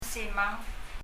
simang　　[si:məŋ]
発音